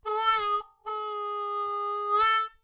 口琴练习剪辑 " 口琴节奏09
描述：这是M. Honer Marine Band Harmonica的13洞上演奏的节奏音轨的录音。
Tag: 口琴 节奏 G